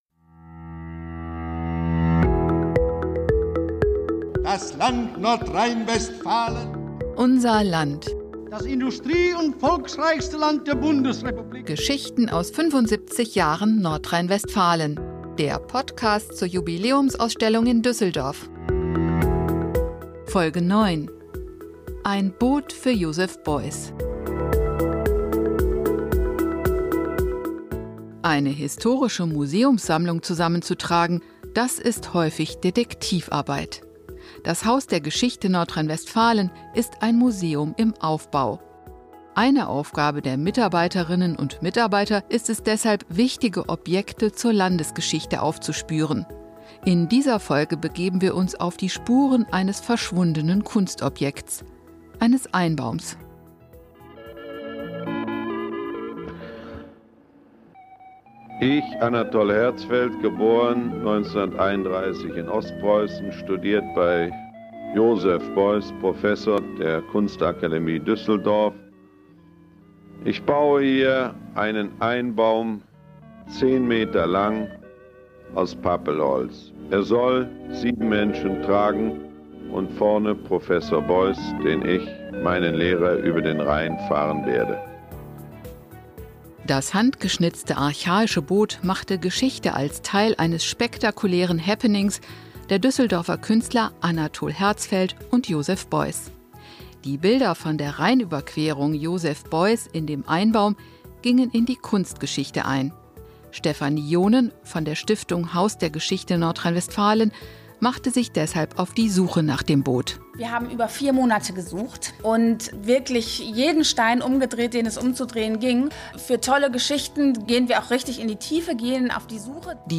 "Unser Land" ist der Podcast über die bewegte und bewegende Geschichte Nordrhein-Westfalens. Wir führen euch an verborgene Orte, sprechen mit Zeitzeugen und erzählen faszinierende Geschichten aus 75 Jahren NRW.